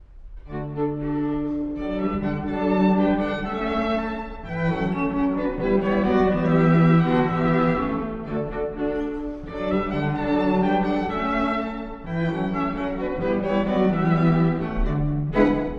↑古い録音のため聴きづらいかもしれません！（以下同様）
Finale ~Allegro giusto~
民族舞曲風の最終楽章です。